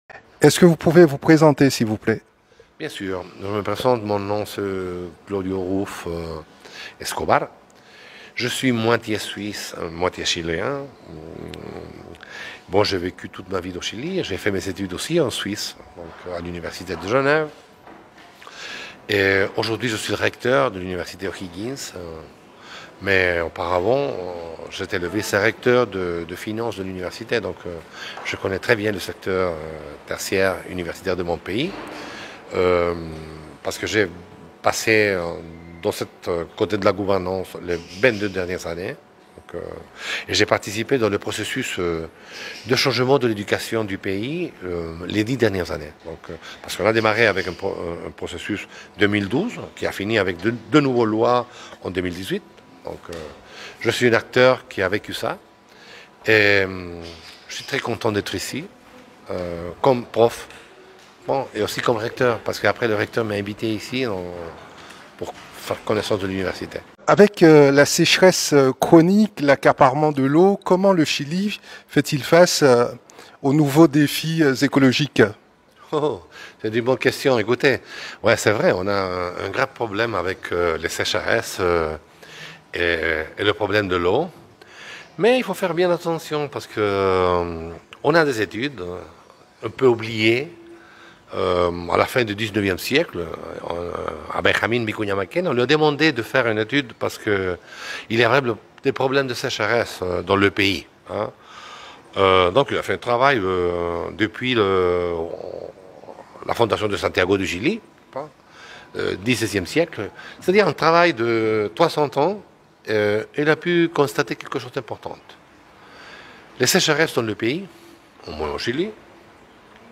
Au micro de Radio Mayouri Campus, il nous parle des enjeux écologiques dans son pays, les tensions qui secouent le Chili et les efforts réalisés dans le domaine de l'éducation.